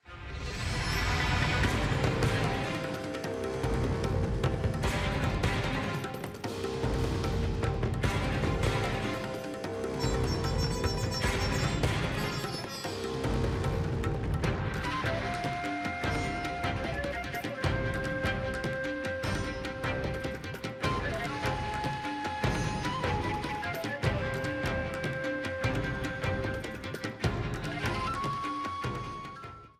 A yellow streamer theme
Ripped from the game
clipped to 30 seconds and applied fade-out